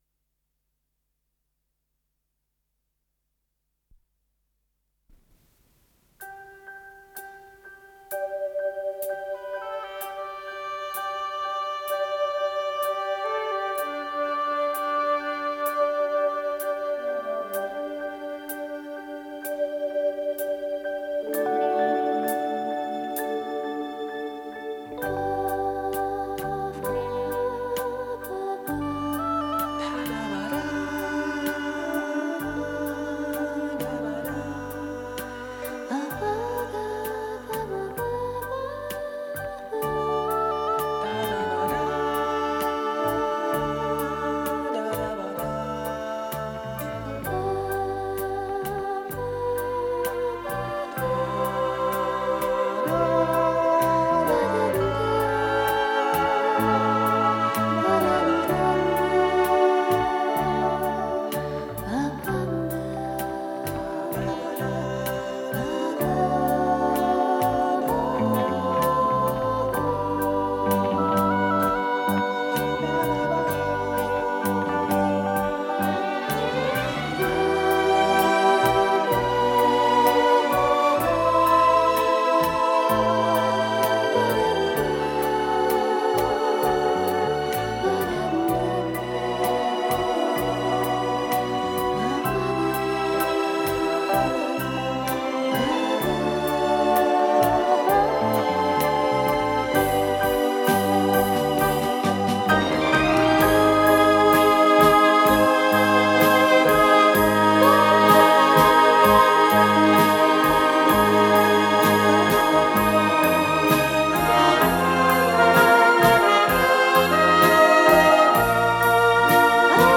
с профессиональной магнитной ленты
ПодзаголовокИнструментальный вариант песни
ВариантДубль моно